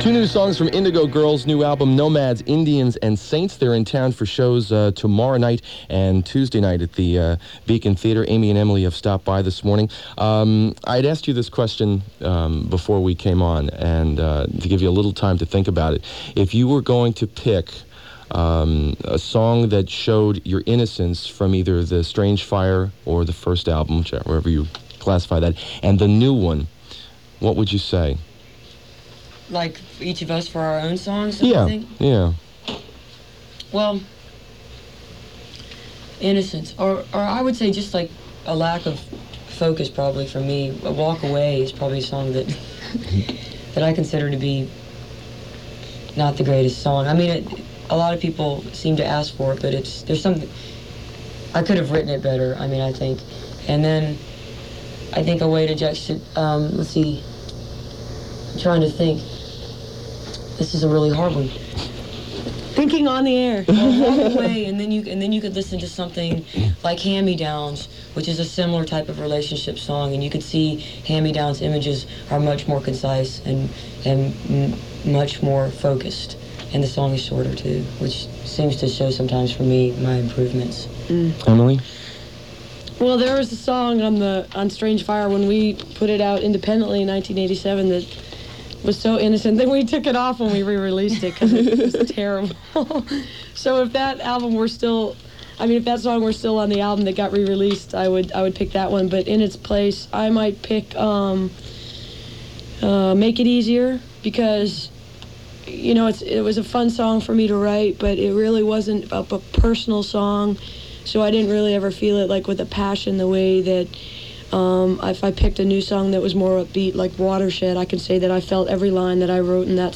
(acoustic duo show)
06. interview (3:08)